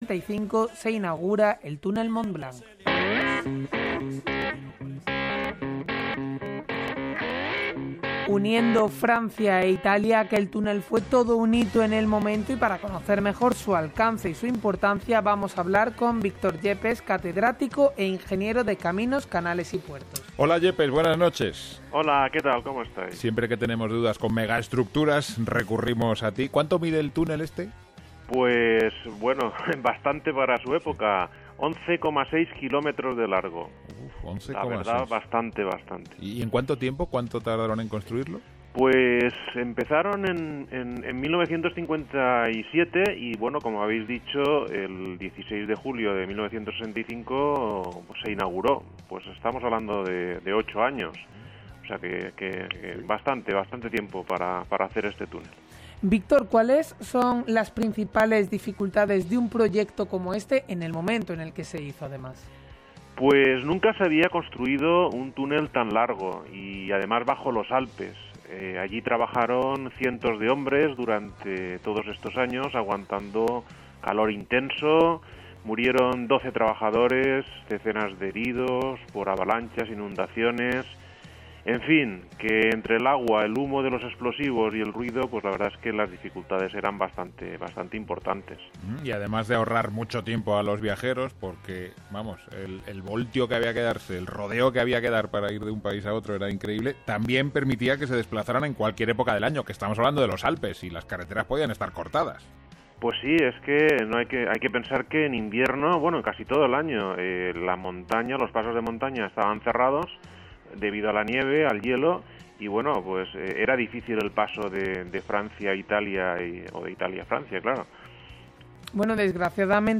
De este aniversario se hizo eco el programa de Radio Nacional de España “Gente despierta”. Me hicieron una pequeña entrevista que os dejo a continuación.